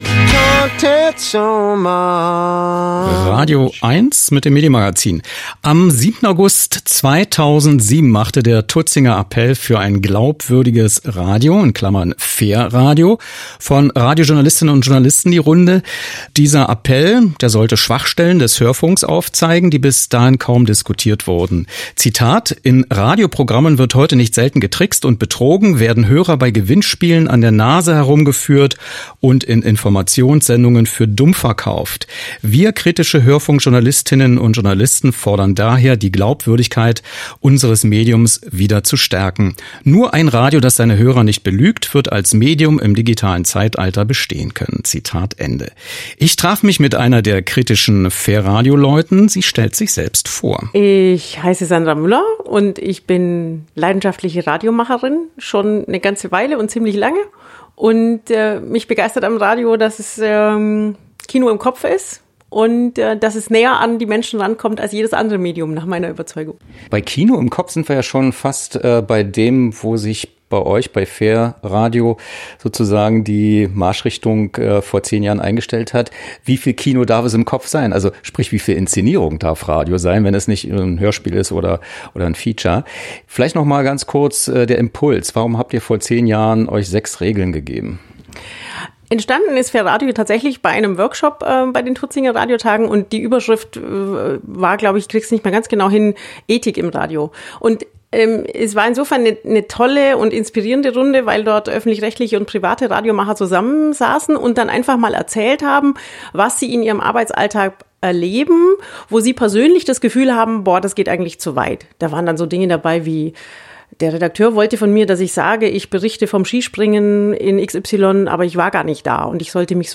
Interview über 10 Jahre Fair Radio